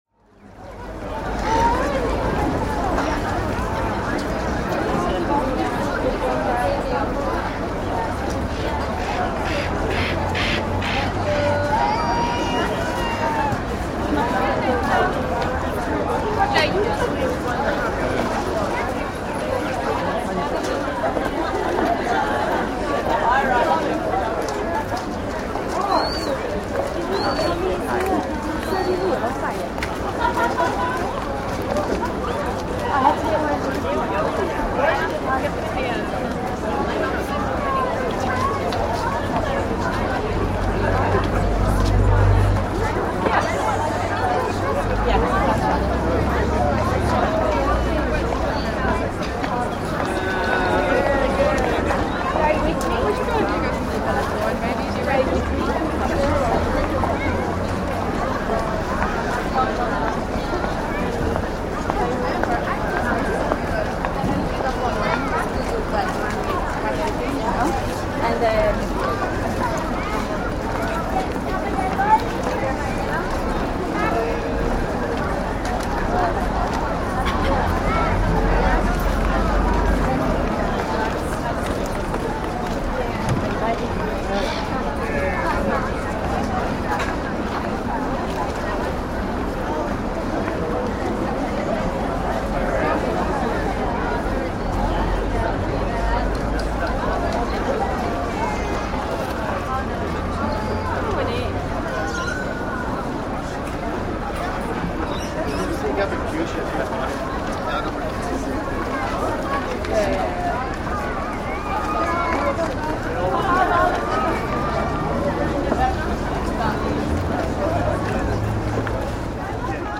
Шум австралийского рынка под открытым небом